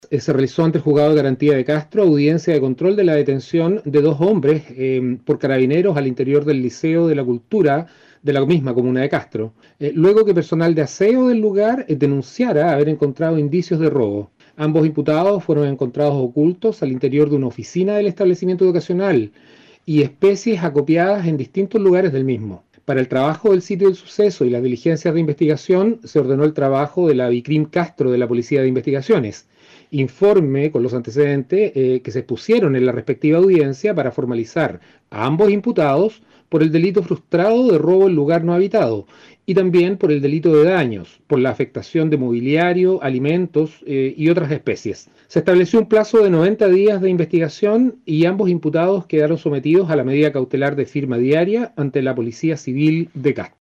Respecto a la audiencia se refirió el Fiscal de Castro, Enrique Canales: